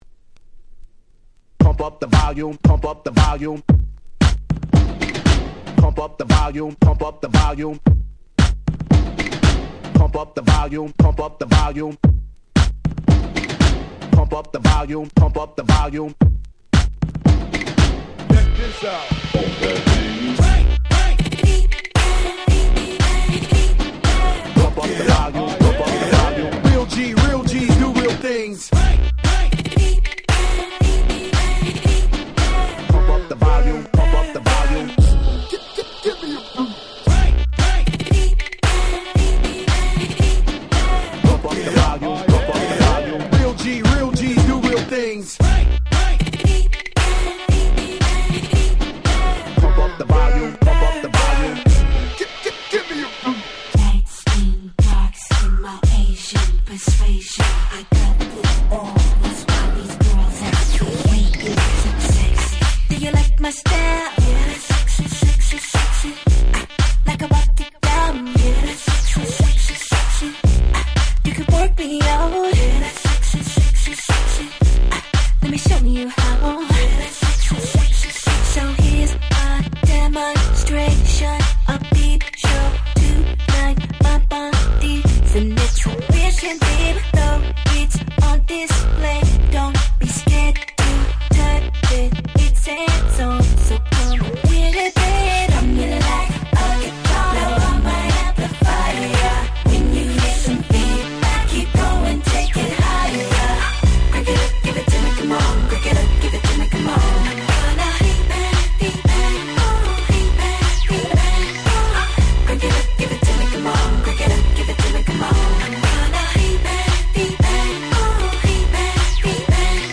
08' Smash Hit R&B !!
どのRemixもフロア映えする即戦力なRemixです！